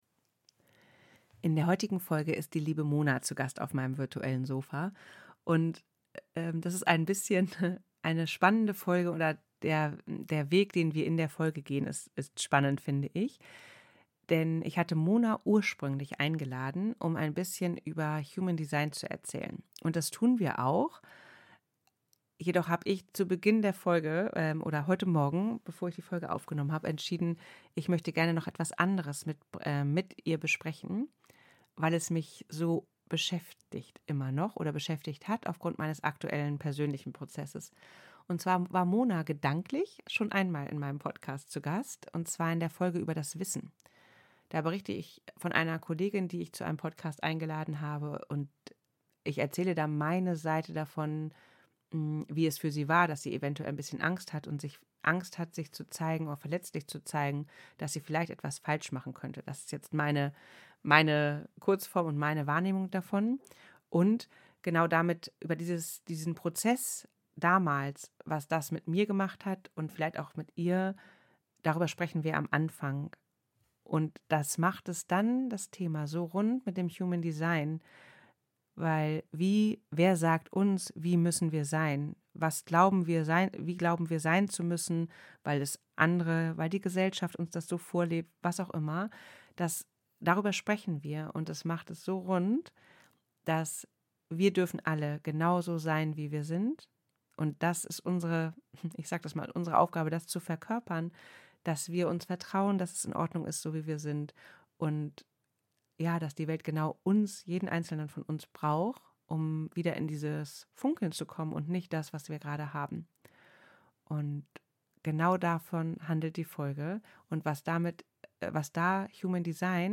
ein Sofa-Gespräch